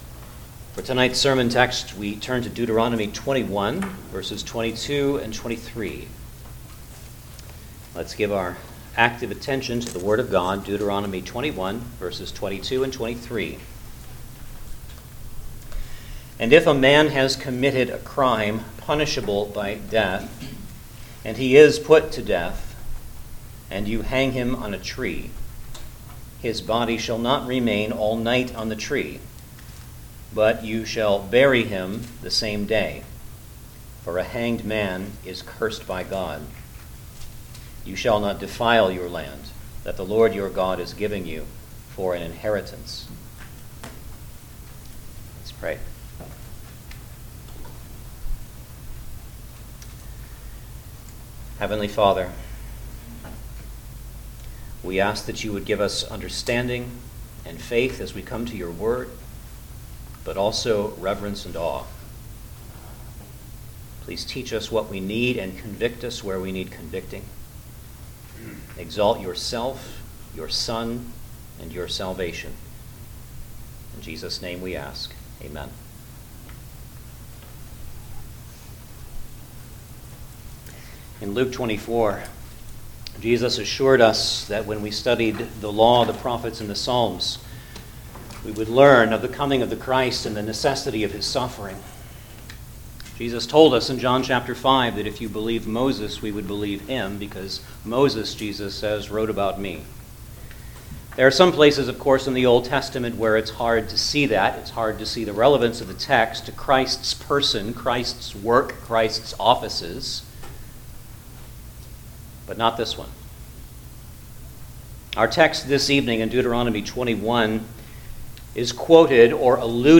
Deuteronomy Passage: Deuteronomy 21:22-23 Service Type: Sunday Evening Service Download the order of worship here .